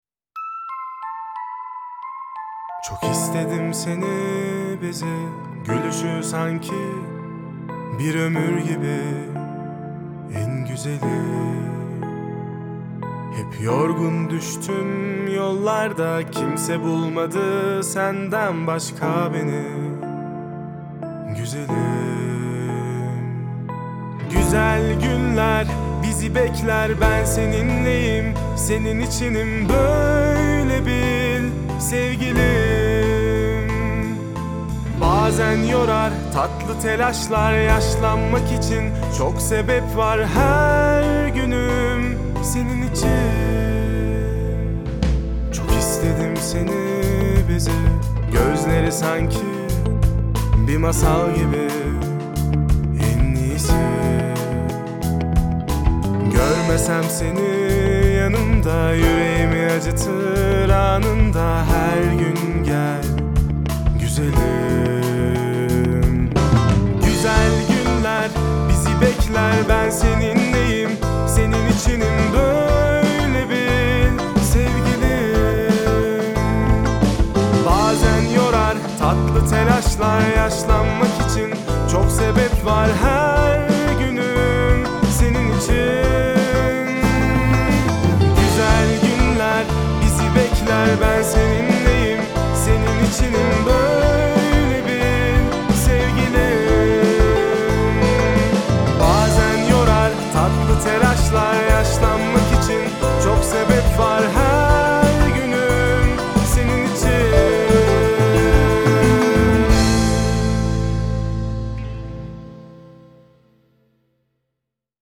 duygusal hüzünlü rahatlatıcı şarkı.